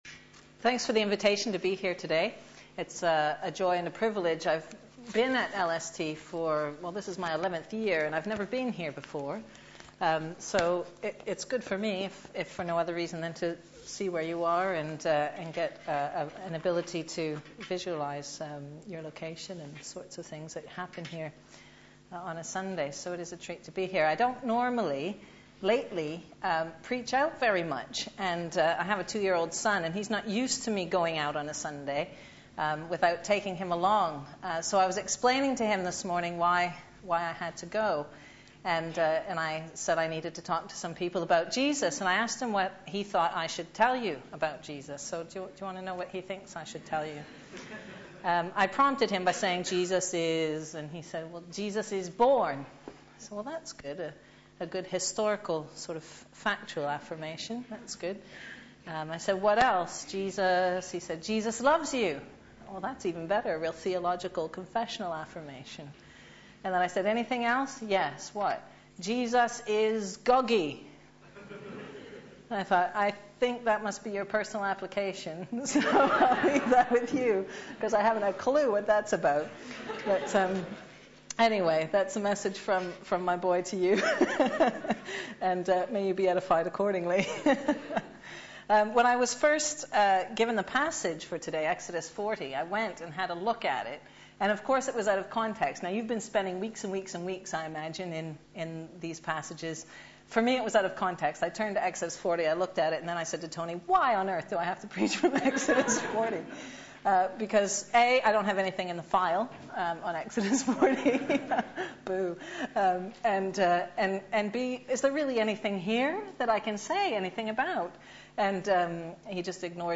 Media for Sunday Service
Theme: The Glory Displayed Sermon